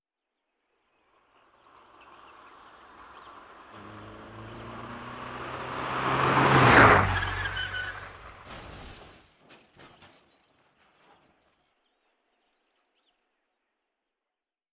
Accidente de coche: coche que pasa y se estrella
Sonidos: Especiales
Sonidos: Transportes